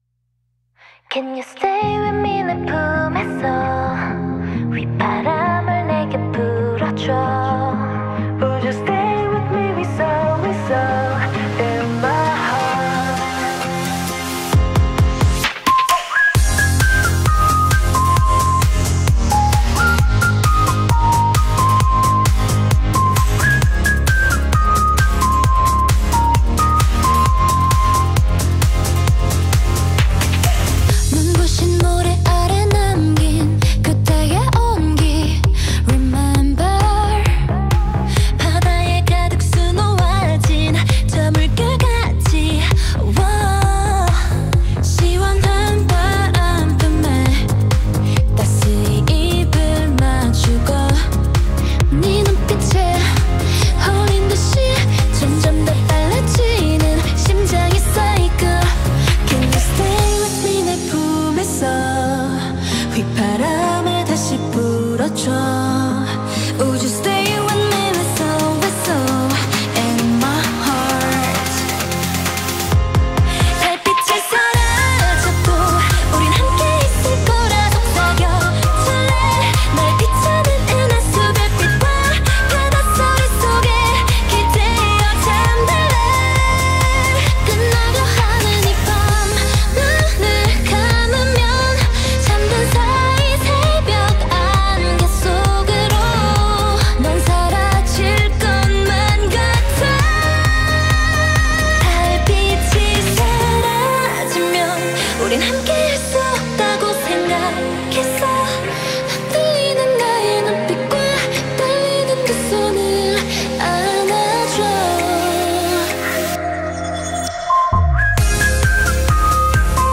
BPM132
Audio QualityMusic Cut
This one gives off a much more chill vibe